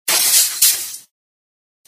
glassbreak2.ogg